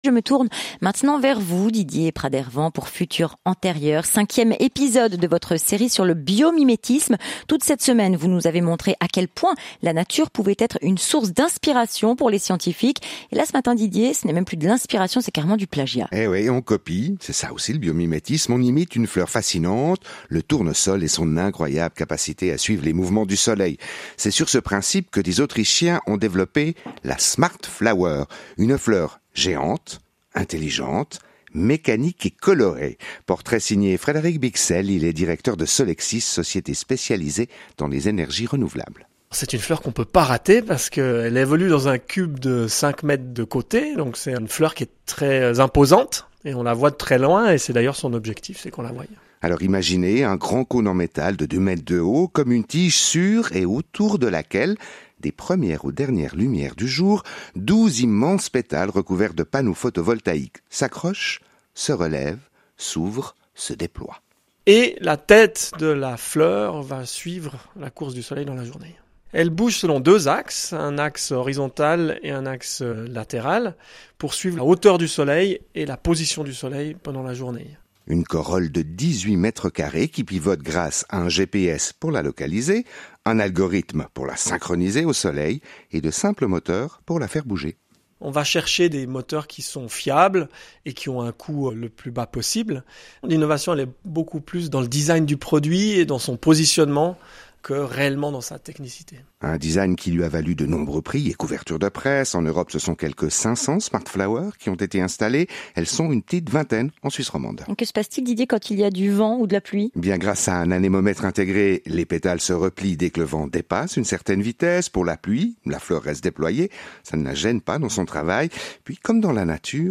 Interview RTS la 1ère –
par RTS la 1ère, futur antérieur